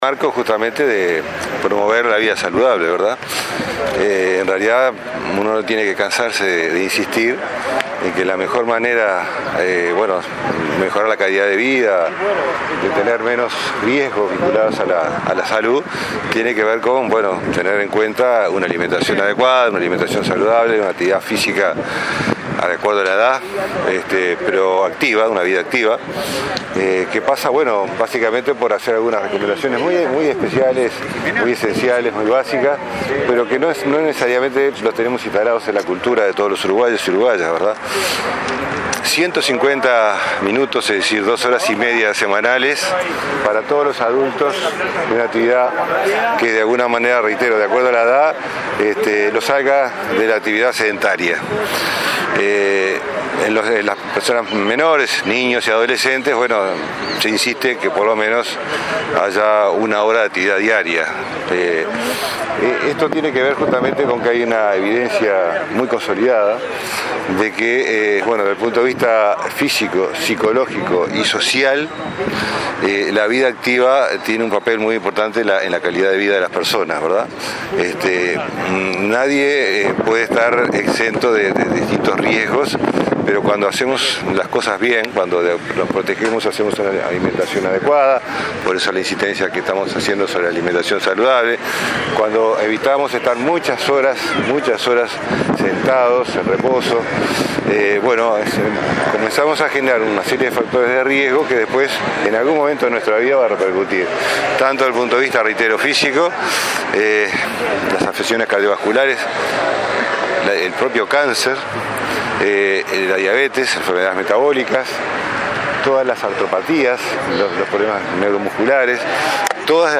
Dos horas y media semanales de actividad física para los adultos y una hora diaria para los niños es la recomendación para mantener una vida activa y mejorar su calidad. Así lo subrayó el ministro de Salud Pública, Jorge Basso, durante la presentación de la guía “¡A moverse!”.